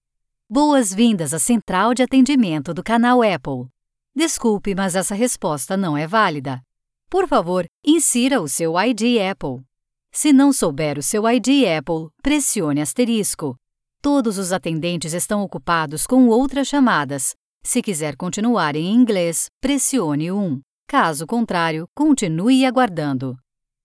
IVR
My professional and well-equipped Home Studio provides me with the ability to offer a QUICK turnaround to clients around the world, whenever needed.
✦Warm, soft, low-pitched, friendly, excellent diction, trusted voice.